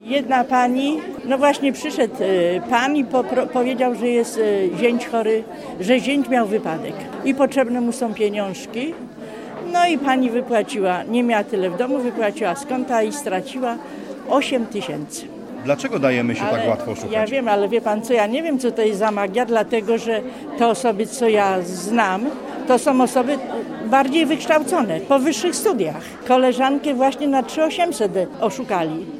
W Sieradzu zorganizowano debatę o bezpieczeństwie dla seniorów.